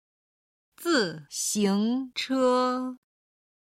准备　(zhǔn bèi)　準備する